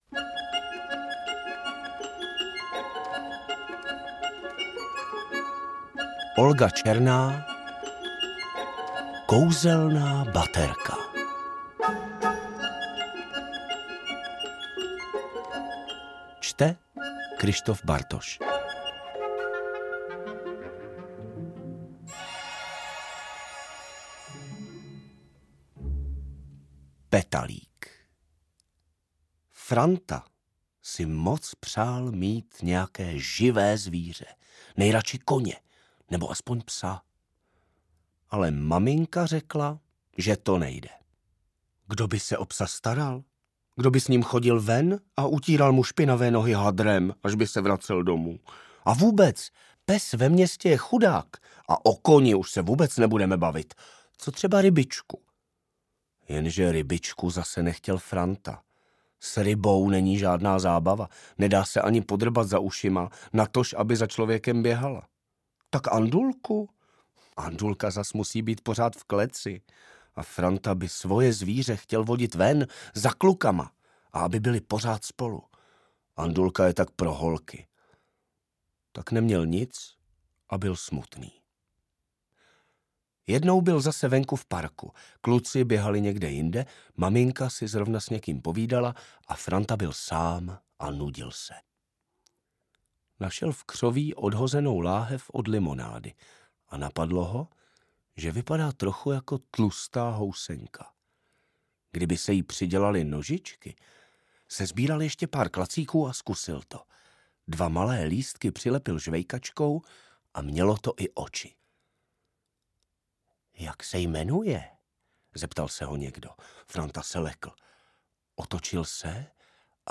AudioKniha ke stažení, 7 x mp3, délka 43 min., velikost 39,1 MB, česky